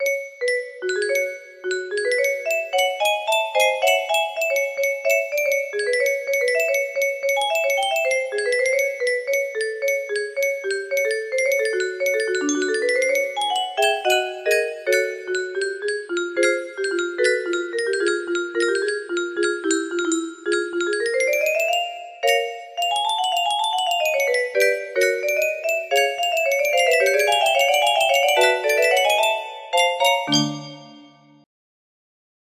Hunted music box melody